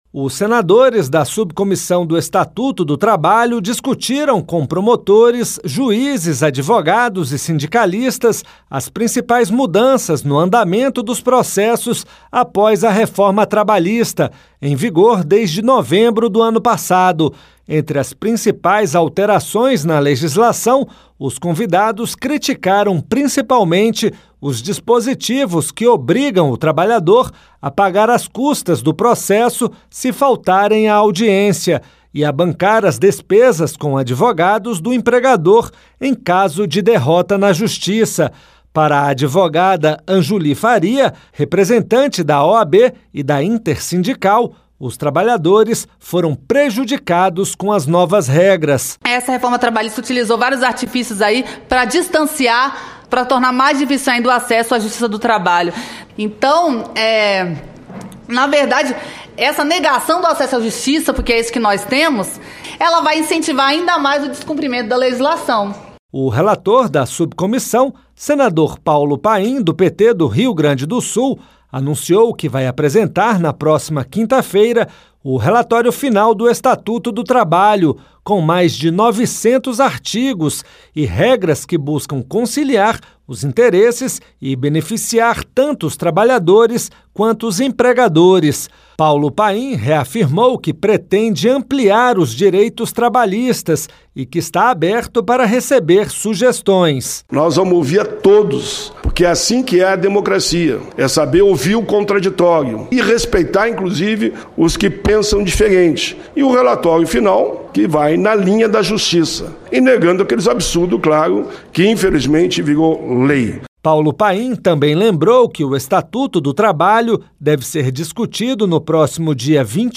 Subcomissão Temporária do Estatuto do Trabalho (CDHET) promove audiência interativa para debater direito processual do trabalho.